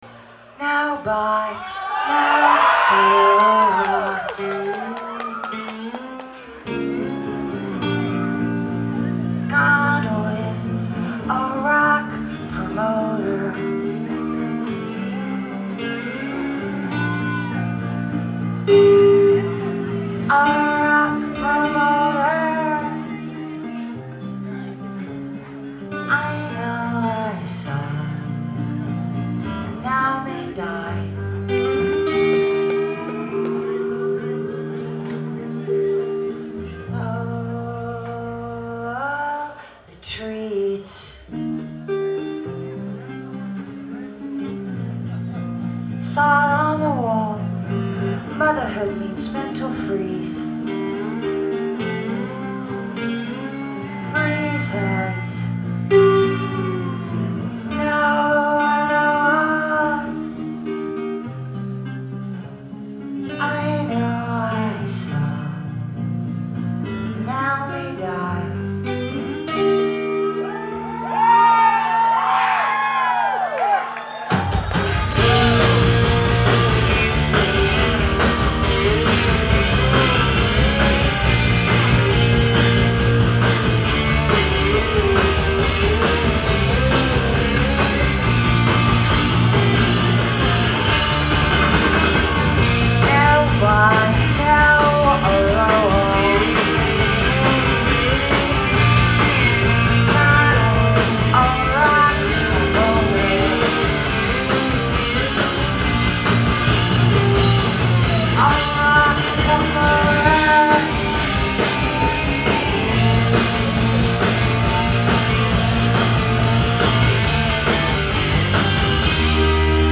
Live at the Paradise
in Boston, MA